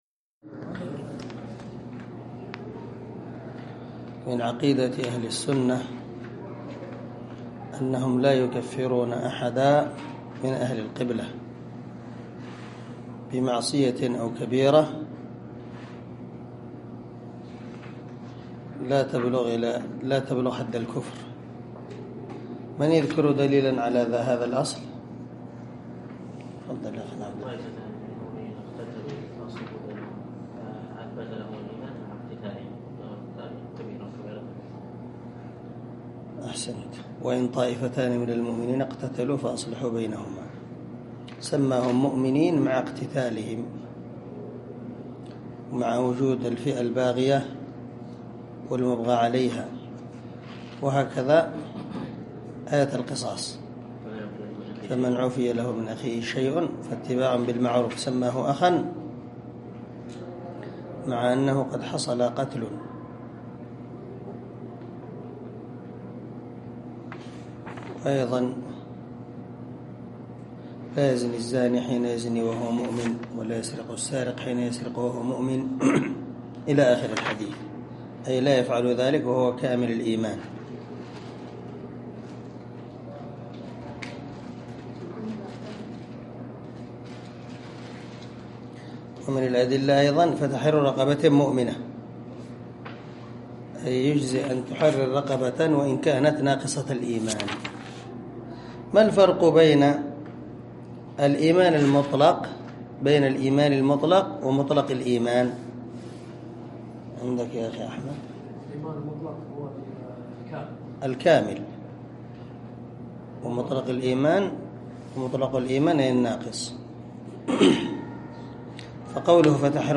عنوان الدرس: الدرس الخامس والسبعون
دار الحديث- المَحاوِلة- الصبيحة.